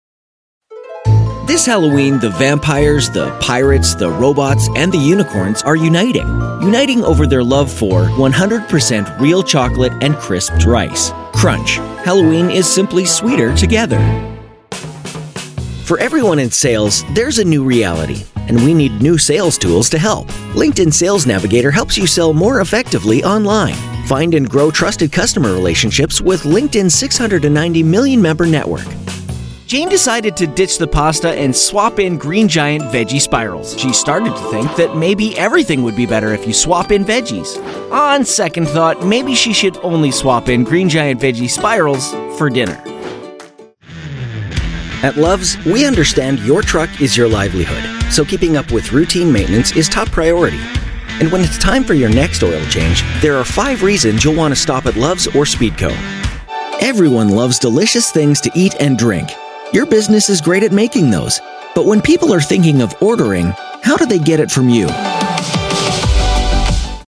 Englisch (Kanadisch)
Real, Animiert, Spaßig, Freundlich, Energiegeladen, Authentisch, Konversationell
Vertrauenswürdig
Warm
Autorisierend